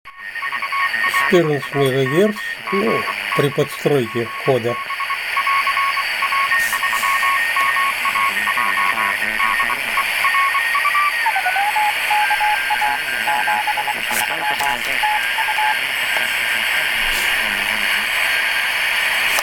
Увы, помехи от радиовещательных станций, всё равно имеются.
Я покрутил, записи прилагаю, можно уменьшить помехи, заодно с чувствительностью...